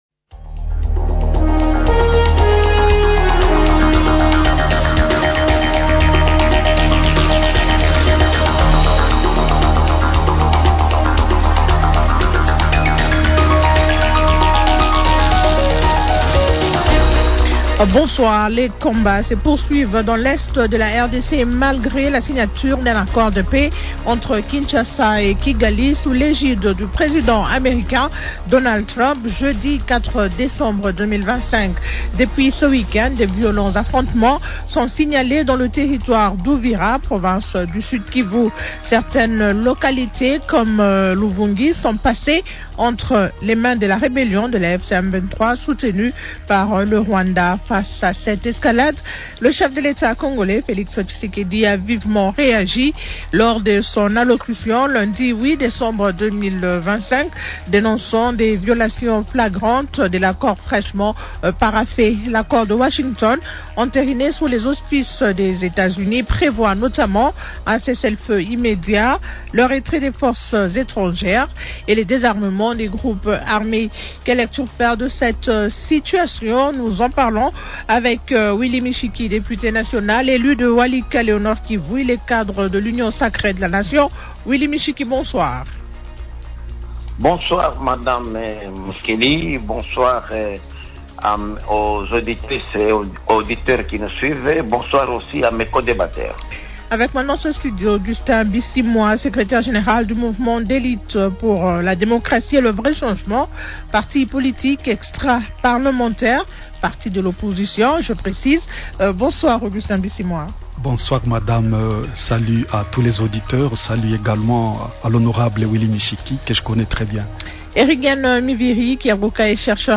Invités Willy Mishiki, député national élu de Walikale au Nord-Kivu.